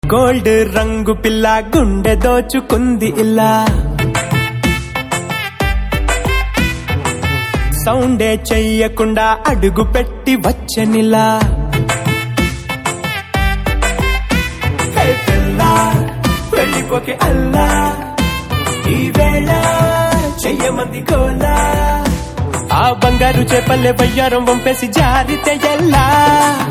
best flute ringtone download | dance song ringtone